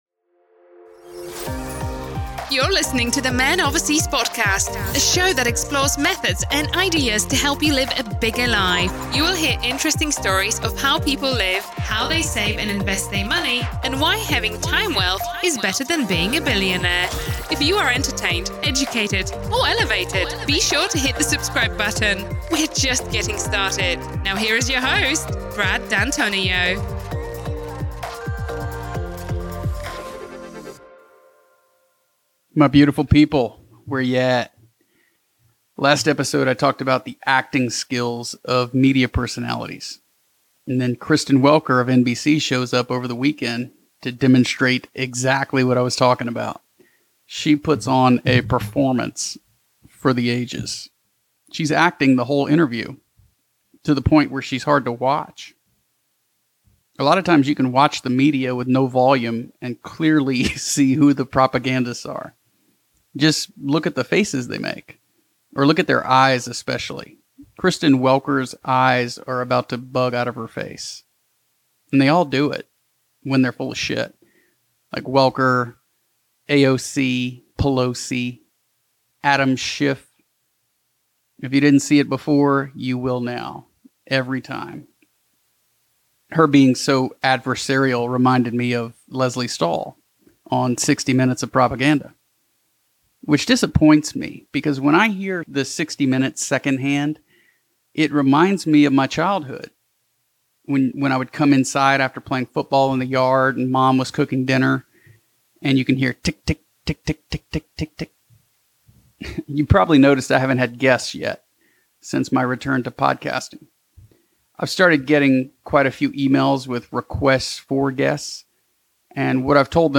In this 9th solo episode, I talk about why we’re in Mexico.